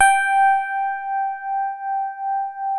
TINE HARD G4.wav